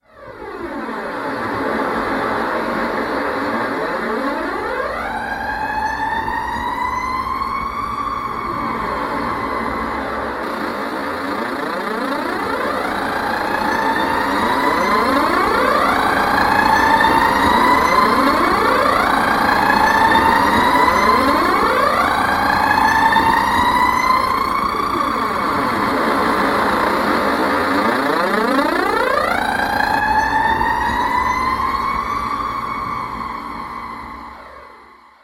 描述：在加拉加斯市录制的公交车发动机，试镜过程
Tag: 场记录 街道 过程 总线 发动机